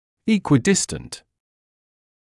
[ˌiːkwɪ’dɪstənt], [ˌekwɪ’dɪstənt][ˌиːкуи’дистэнт], [ˌэкуи’дистэнт]равноудалённый, эквидистантный